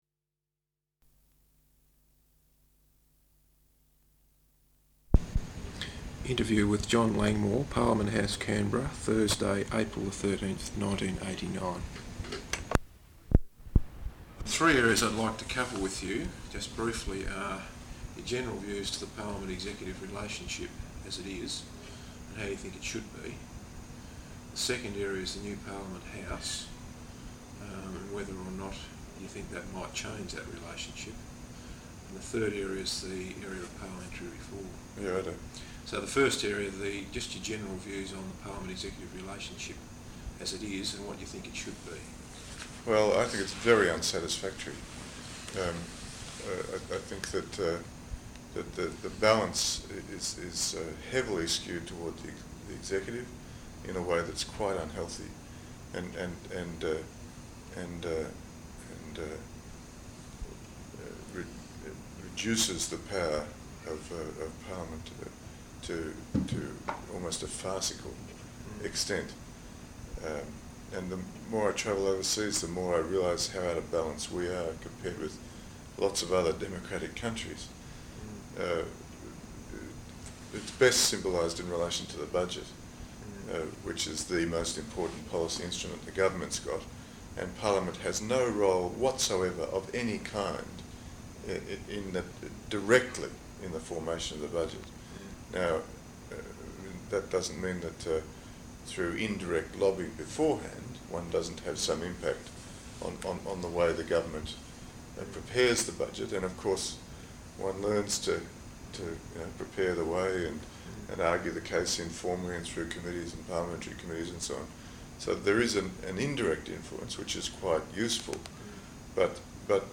Interview with John Langmore, Labor Backbencher, Parliament House, Canberra, Thursday April 13th 1989